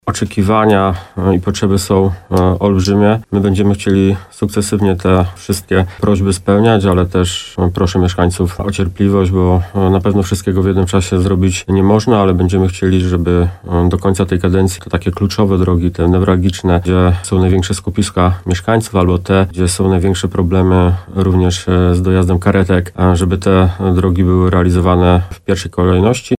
Jak mówi burmistrz Piwnicznej-Zdroju Tomasz Michałowski, to przykład jednego z wielu zaplanowanych remontów dróg w najbliższych latach.